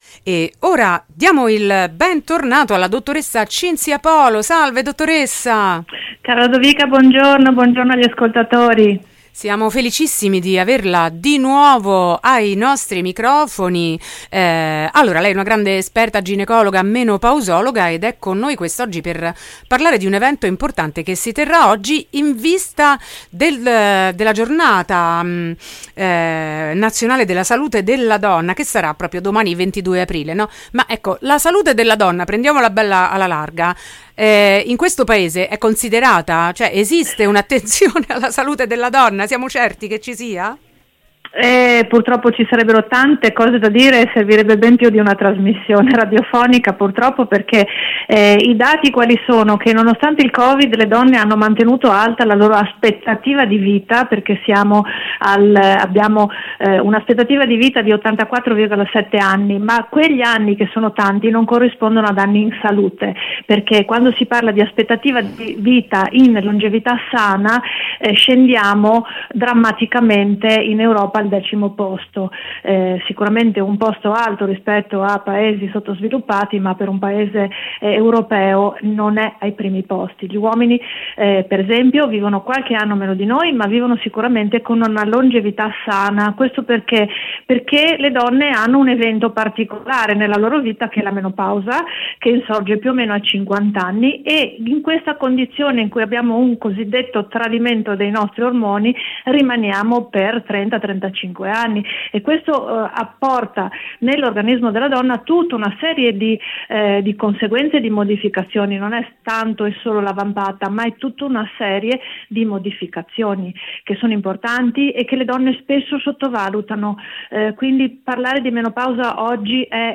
Rivoluzione menopausa: intervista